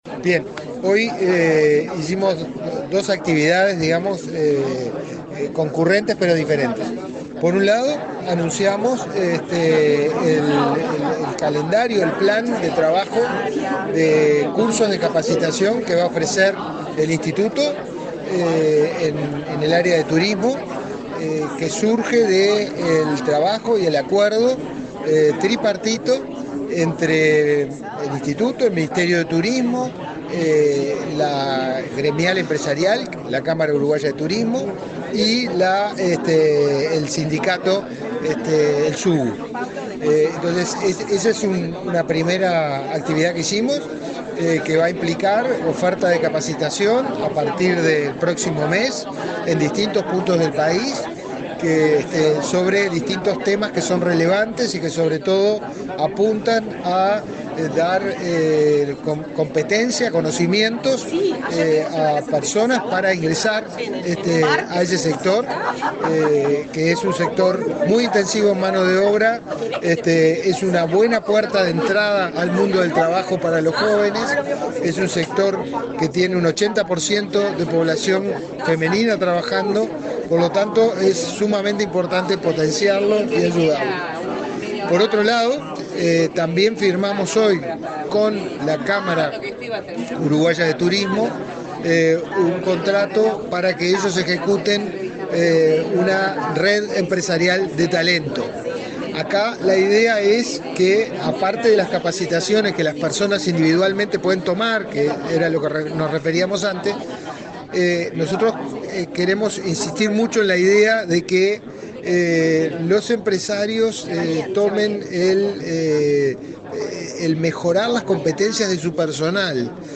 Entrevista al director del Inefop, Pablo Darscht